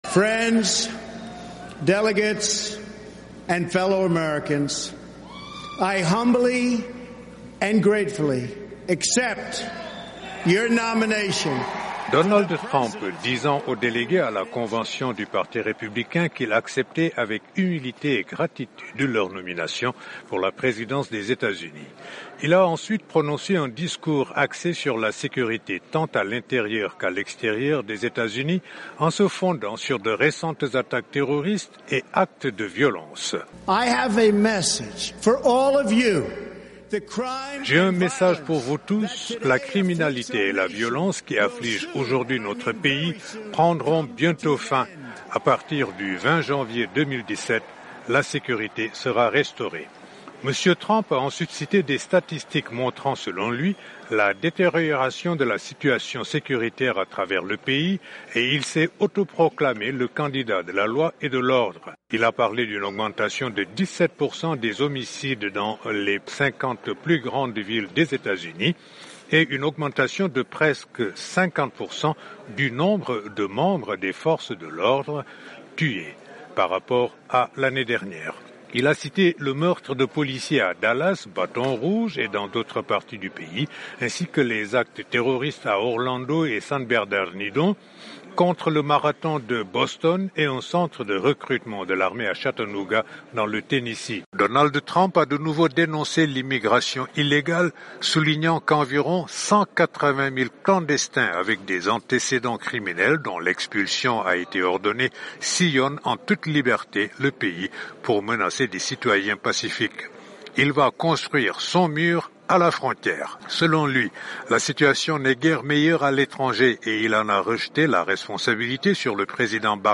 Compte-rendu